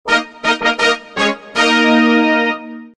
Blooket Win Sound Button Soundboard: Play Instant Sound Effect Button